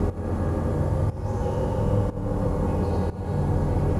Index of /musicradar/sidechained-samples/120bpm